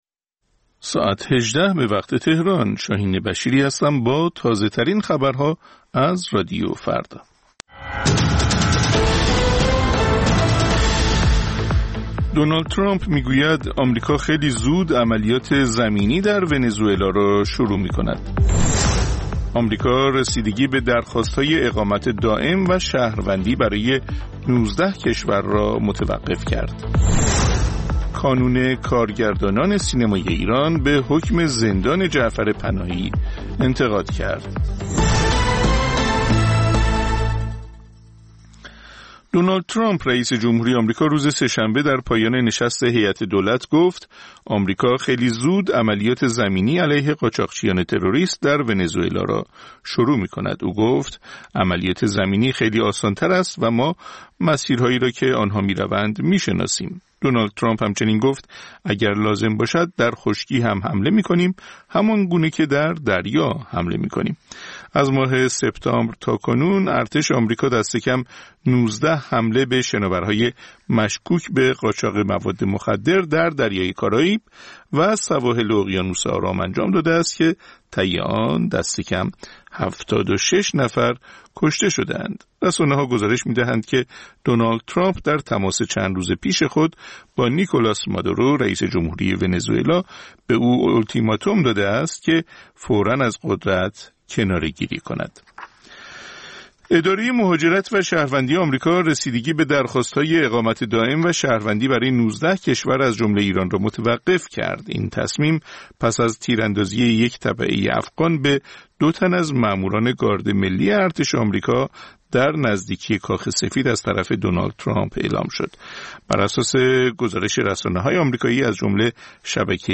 سرخط خبرها ۱۸:۰۰